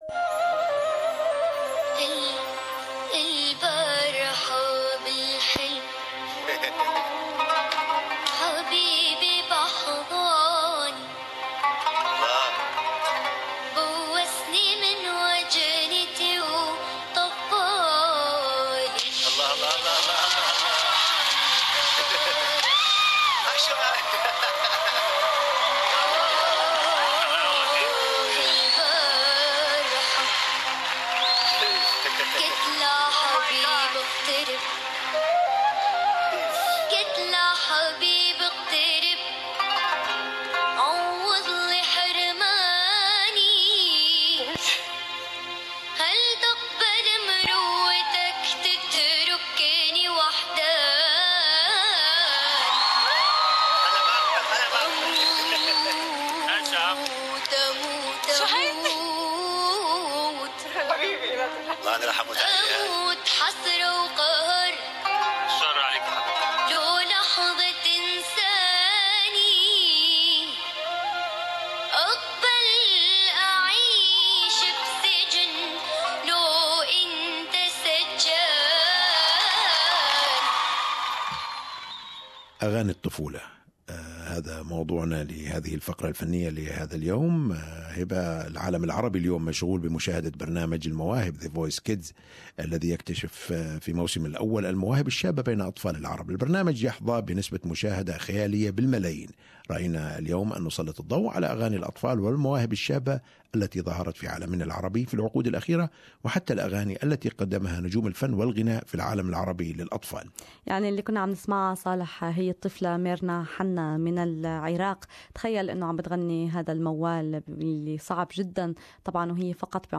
The biggest names in the Arab world have produced and sang songs dedicated for the children. As the Arab world watches The Voice Kids here are some of the biggest hits dedicated and sang by Children